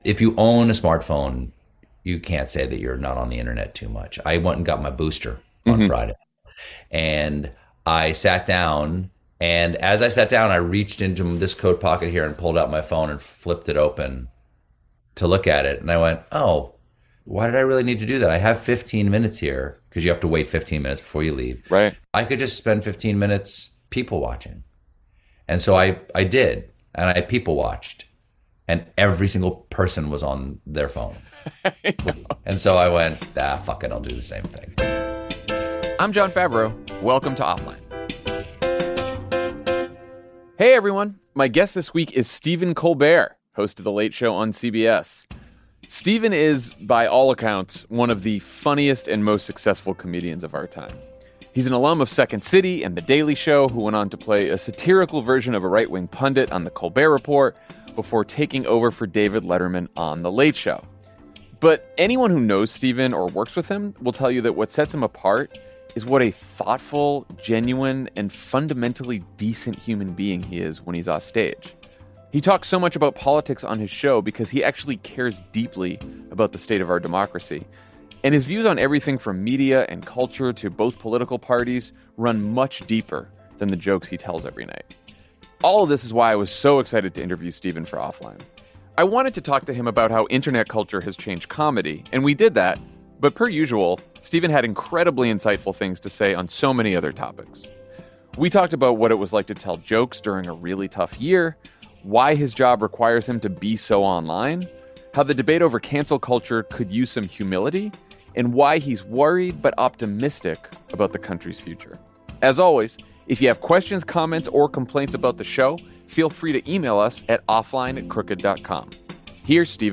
Stephen Colbert joins Jon to defend his 8 hour-a-day screen habit and preach the benefits of a Twitter-free lifestyle. The two talk about what it took to produce The Late Show during the pandemic, why Stephen is glad his live audience is back, and what some of the darkest days of American democracy looked like behind the scenes at the Ed Sullivan Theater. Jon also asks Stephen about his perspective on cancel culture and why comedy must be rooted in empathy.